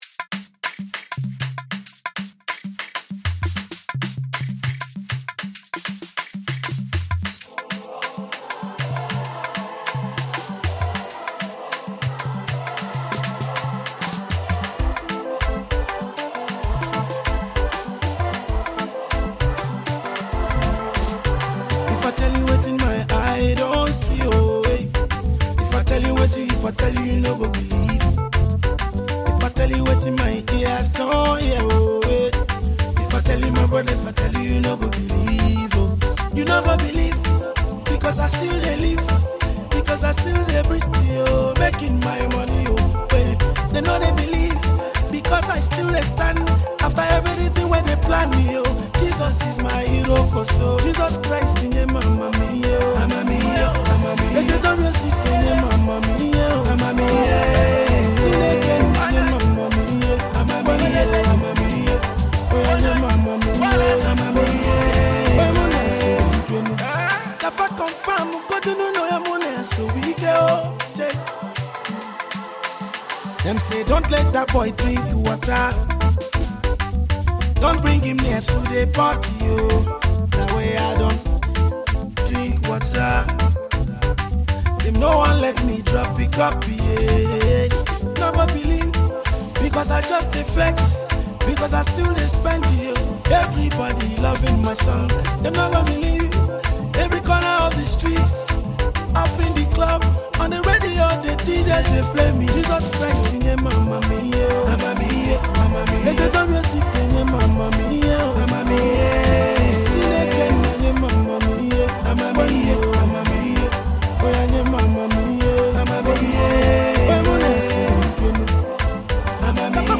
Highlife Music, Igbo Music, Indigenous Pop